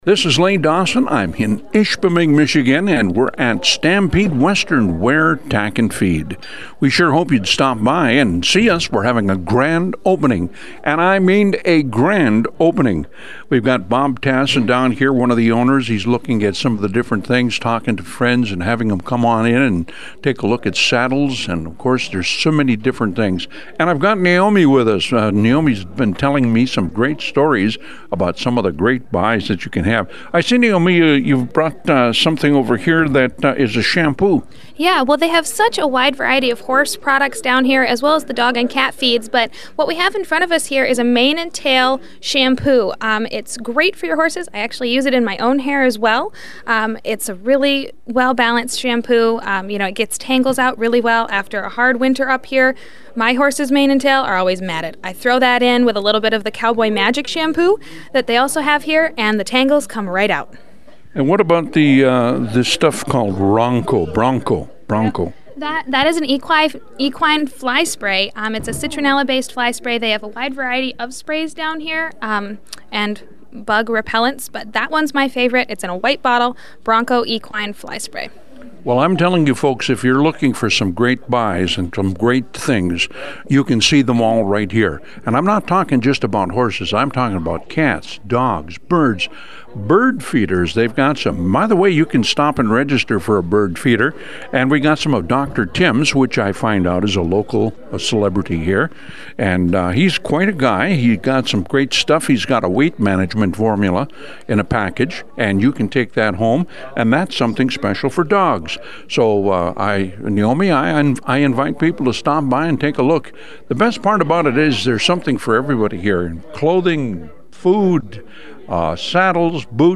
There was fun for everyone at Stampede Western Wear's Grand Opening